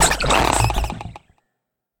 Cri de Délestin dans Pokémon HOME.